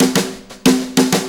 Brushes Fill 69-02.wav